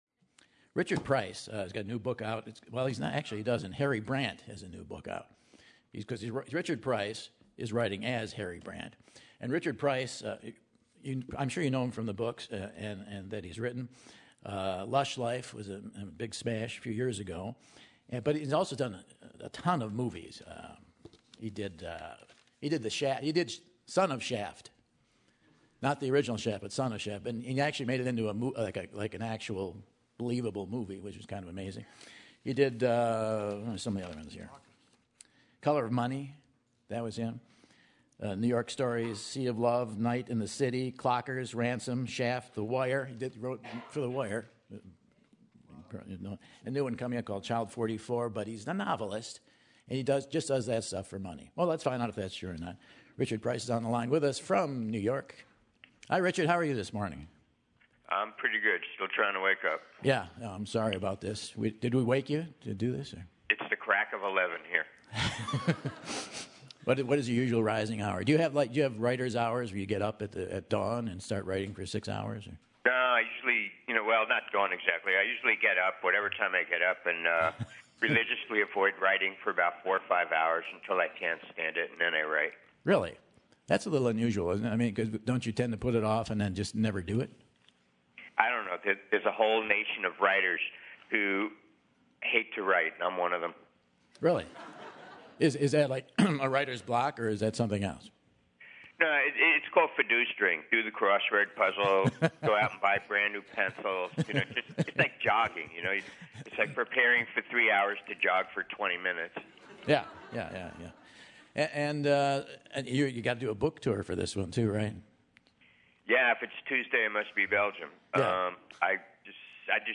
Micahel calls Price up to talk about his most recent work,a detective novel entitled The Whites written under the pen name Harry Brandt. On the air Price gets into the thoughts behind his process, the Milwaukee Braves, Jewish police, the miscalculation of using a pen name and more.